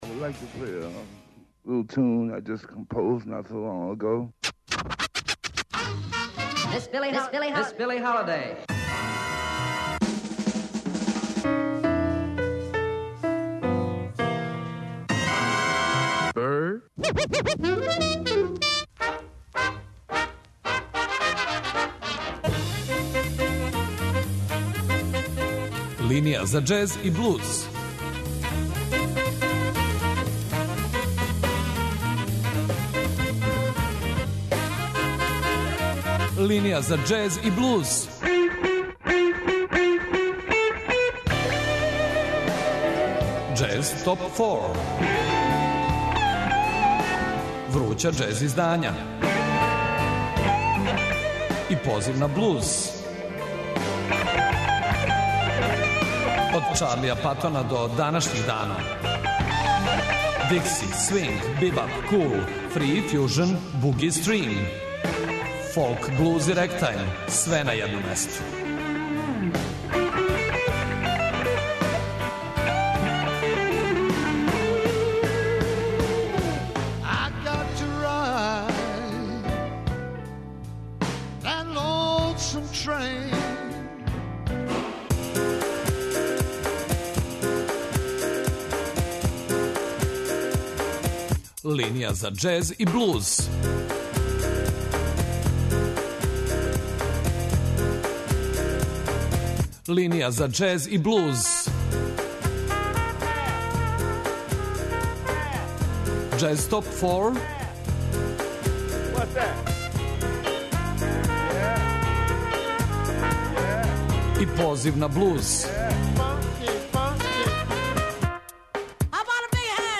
Свако издање биће представљено у оквиру 25-минутног блока, уз кратку причу и музику са албума.